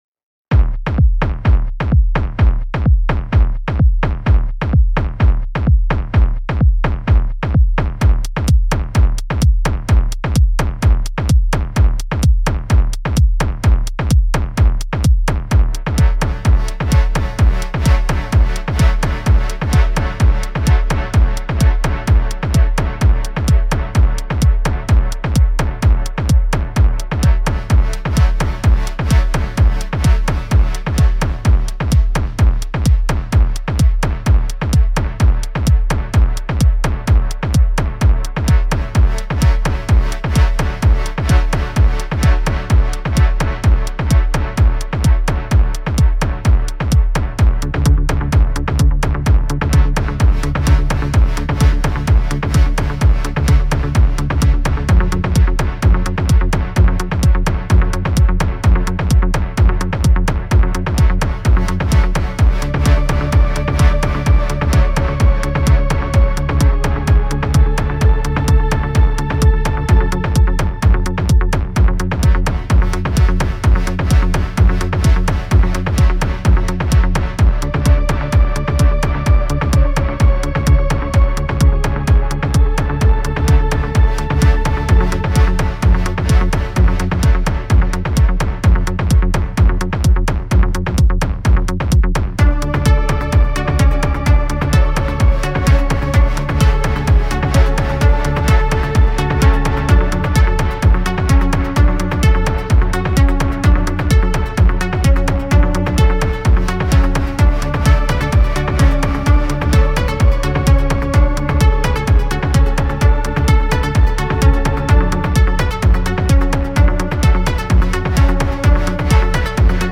techno hypnotic
synthesizer